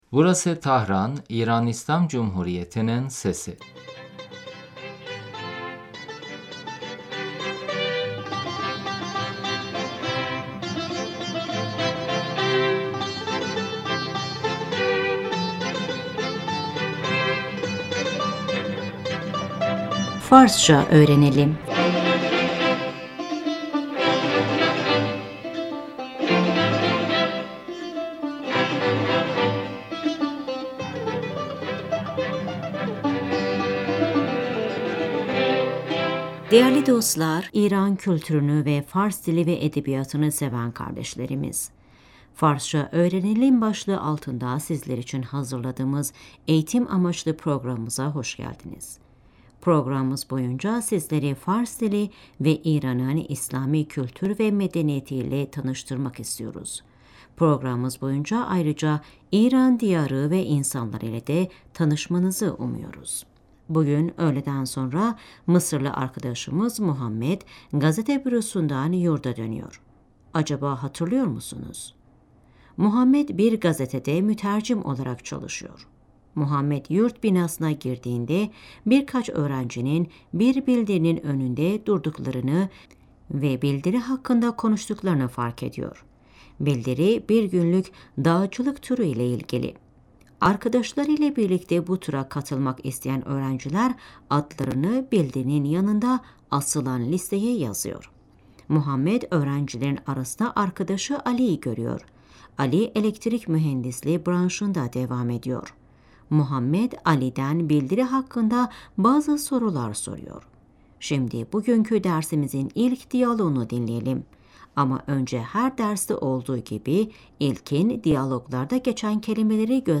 Şimdi Muhammed ve Ali arasında geçen diyaloğu dinleyin ve tekrarlayın.